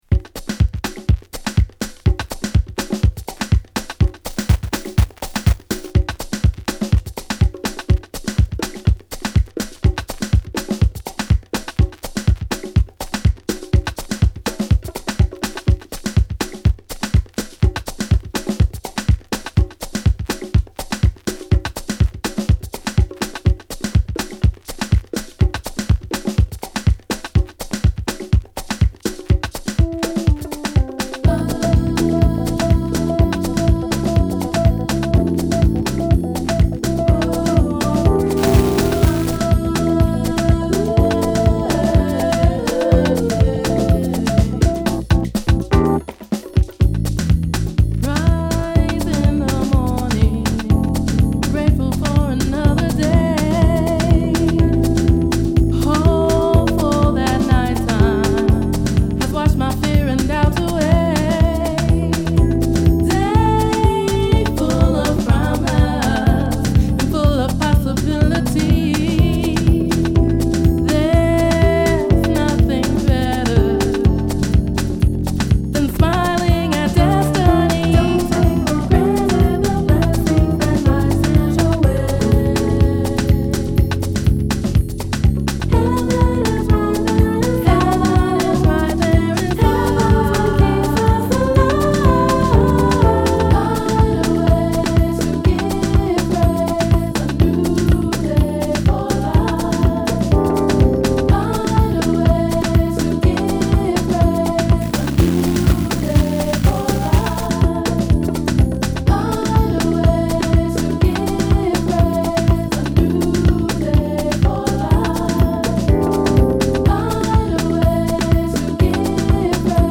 ＊試聴はA→B1→B2です。